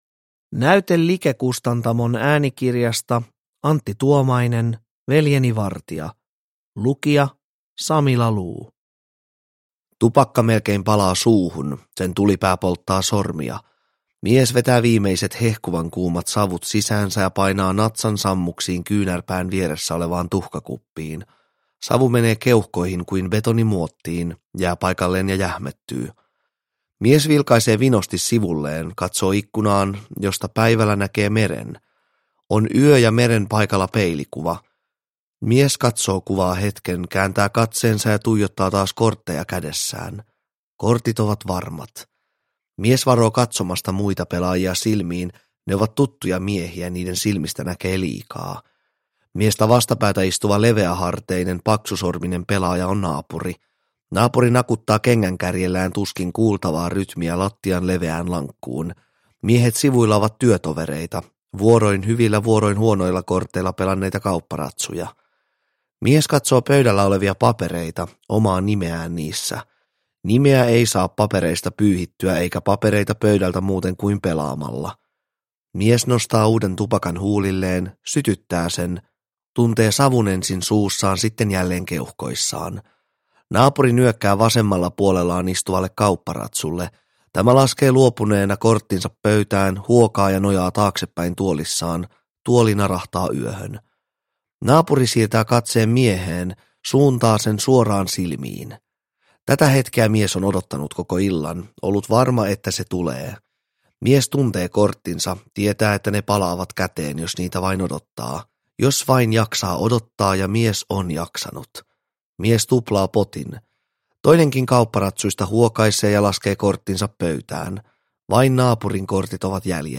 Veljeni vartija – Ljudbok – Laddas ner